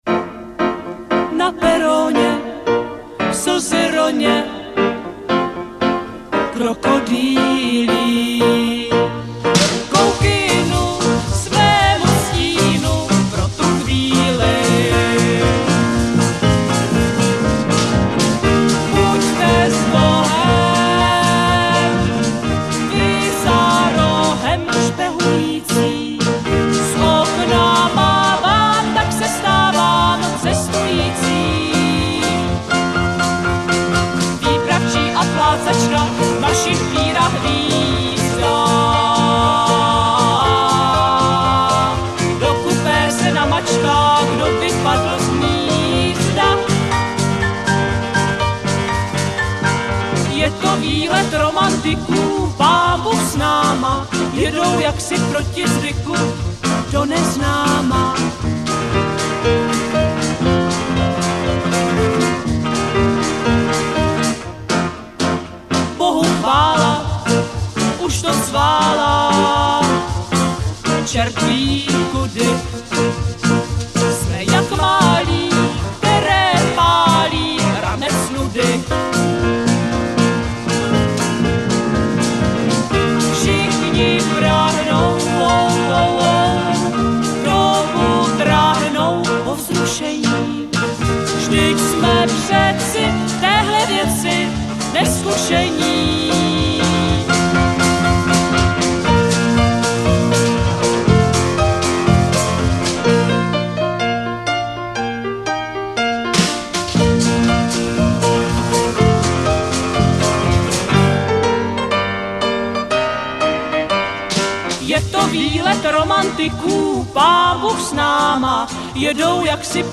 prázdninový pojízdný písničkový kabaret